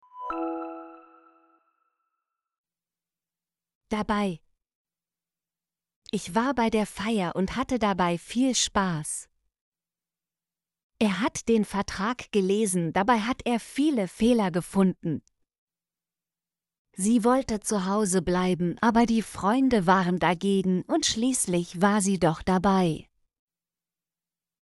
dabei - Example Sentences & Pronunciation, German Frequency List